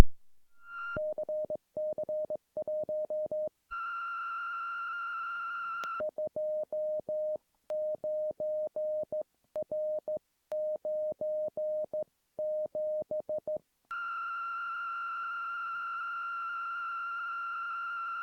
Using Audacity, I was able to finally get a decent recording of the whining noise that my CC1 is making (the recording isn't very loud so you might have to turn the volume up).
The audio clip starts with CC1's startup message ("CC1") followed by the whining noise, and then the Morse code readout of the frequency and then more whining. Of interest I think is that the whining is muted when CC1 is playing the dits and dahs.
Using Audacity's frequency analysis tells me the main frequency of the whine is around 1300 Hz with second and third harmonics at around 2600 and 3900.
CC1Whine.ogg